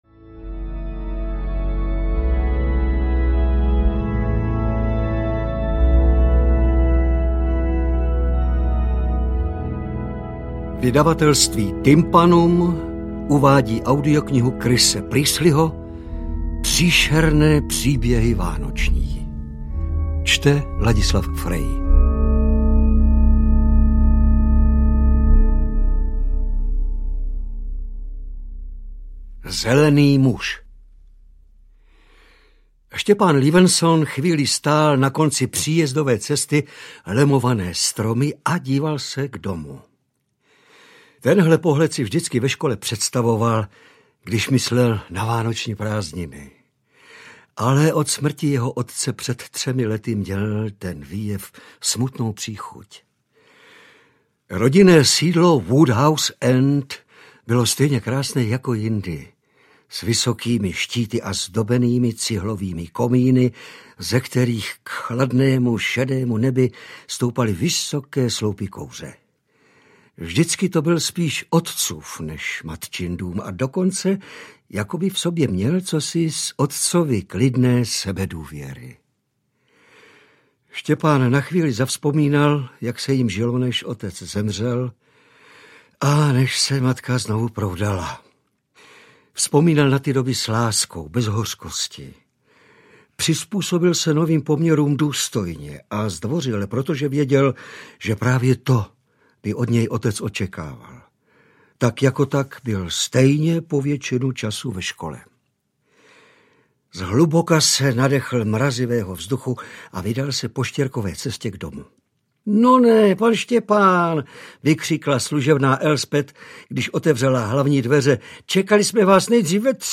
Příšerné příběhy vánoční audiokniha
Ukázka z knihy
• InterpretLadislav Frej